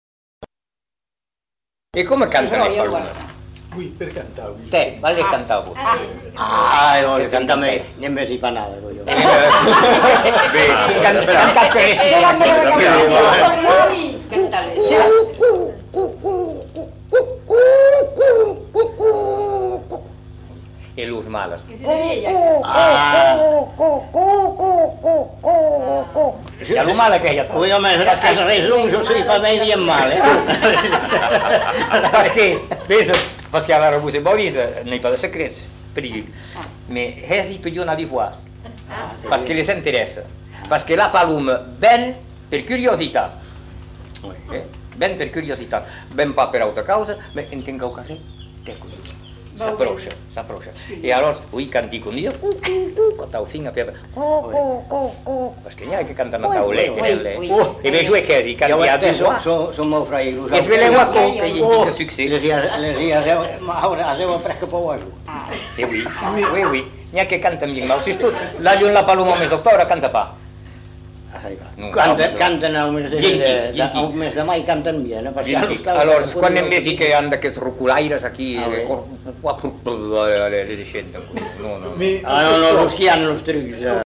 Aire culturelle : Bazadais
Lieu : Bazas
Genre : expression vocale
Effectif : 1
Type de voix : voix d'homme
Production du son : effet de voix